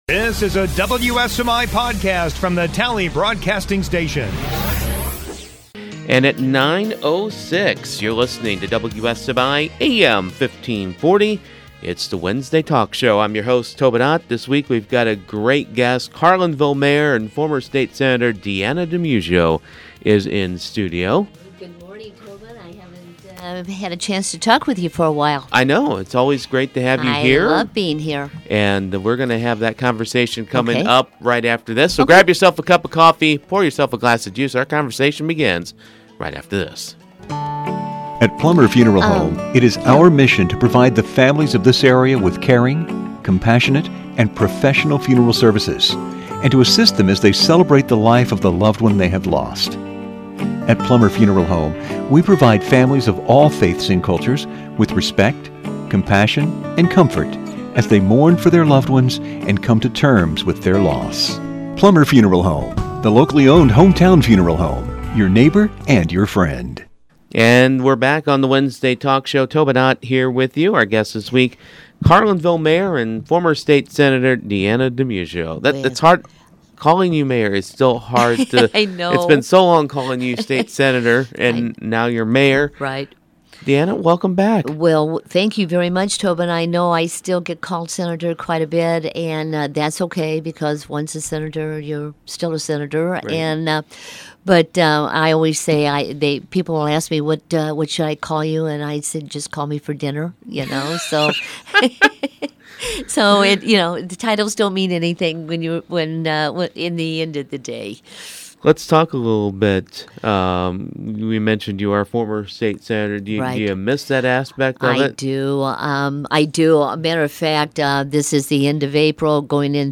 Podcasts - Wednesday Talk
Wednesday Morning Talk Show